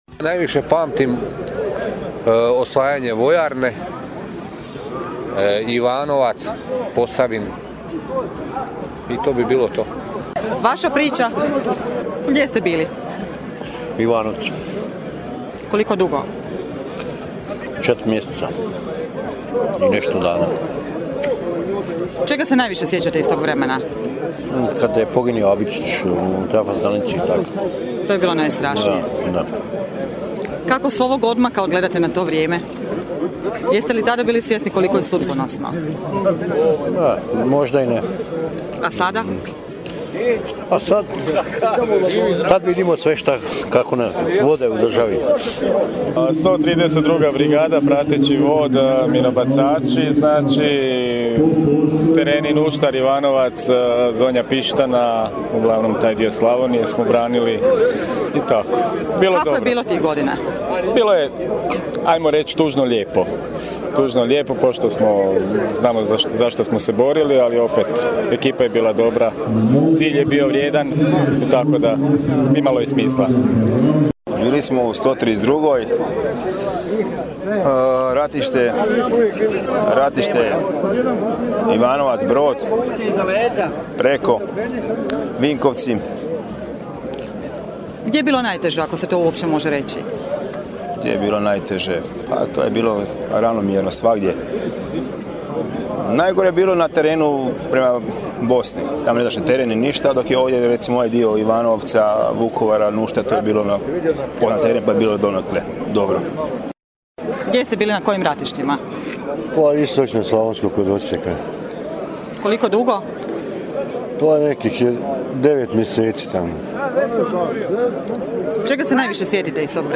34. obljetnicu ustroja čestitamo svim pripadnicima naše 132. brigade, a evo i dojmova ratnika po čemu  pamte to vrijeme ponosa: